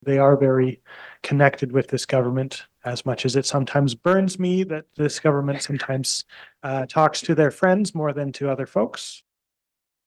At this week’s council meeting, Councillor Jeff Wheeldon made the following statement about Atlas: